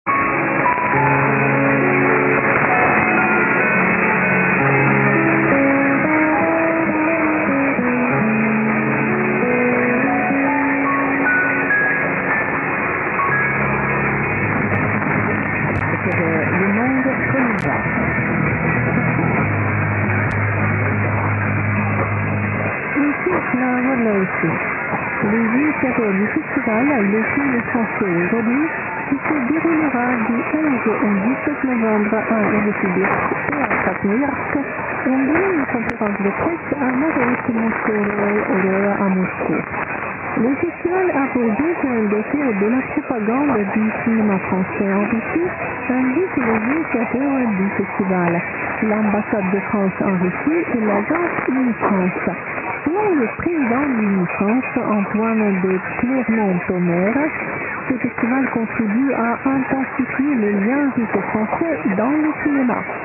MP3 Sound Clips - International Medium-Wave DX
Featured Recordings: Powder Point - Duxbury, MA, USA - 11 NOV 2010
Receiver = Microtelecom Perseus, Antenna = 2 m by 2 m car-roof Micro-SuperLoop to W7IUV Amplifier